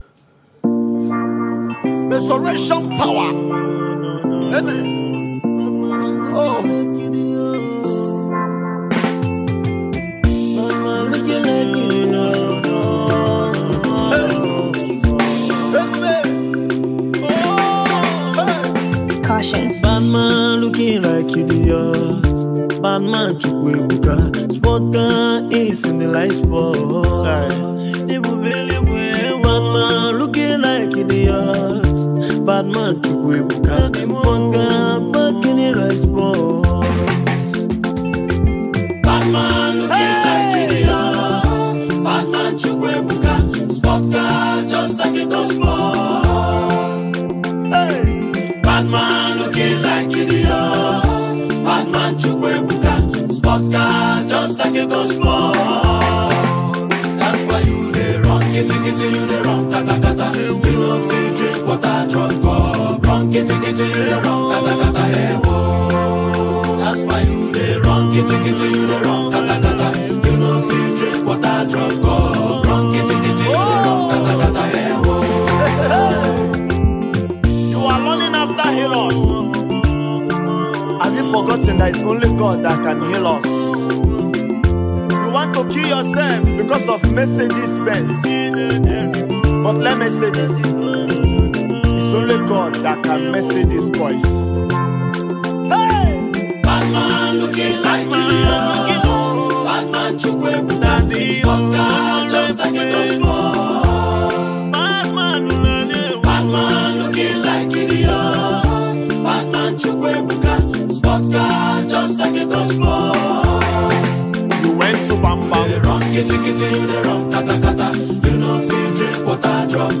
funny rendition